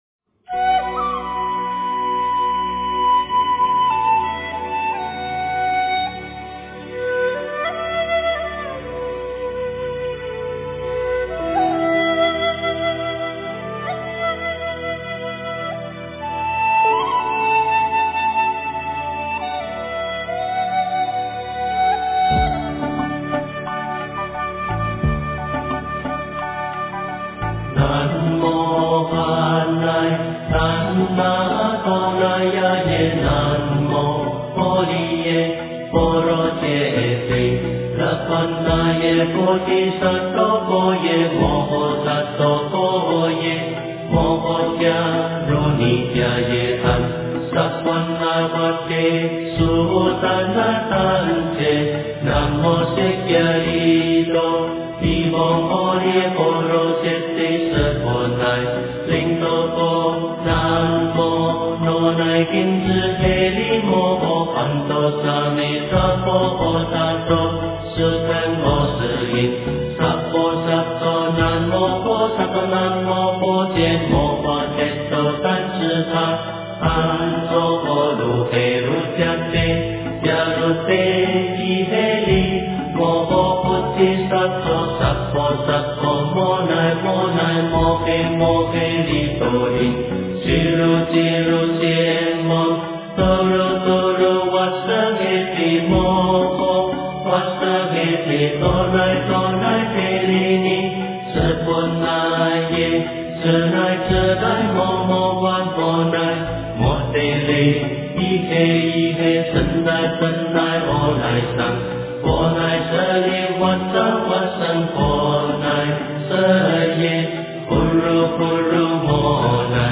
大悲咒-千手千眼观世音菩萨广大圆满无碍大悲心陀罗尼经大悲神咒 诵经 大悲咒-千手千眼观世音菩萨广大圆满无碍大悲心陀罗尼经大悲神咒--佚名 点我： 标签: 佛音 诵经 佛教音乐 返回列表 上一篇： 大悲咒 下一篇： 般若波罗蜜多心经 相关文章 五木摇篮曲--未知 五木摇篮曲--未知...